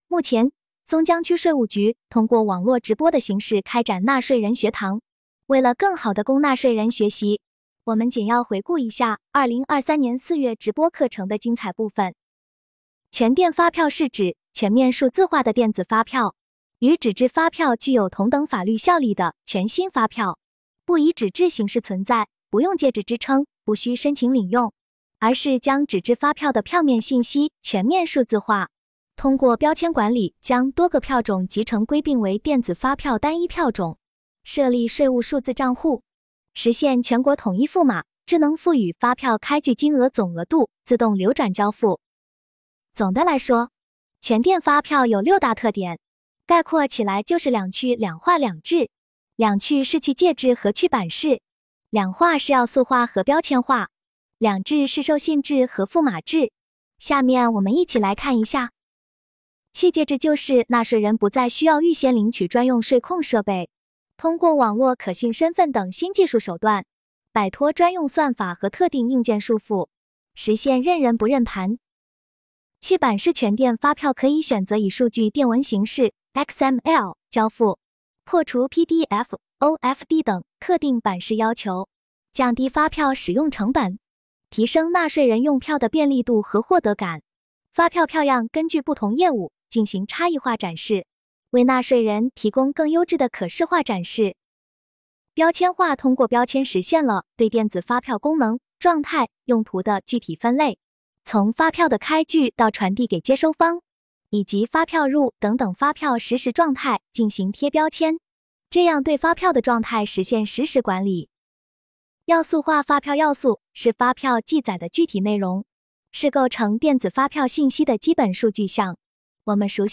直播课程一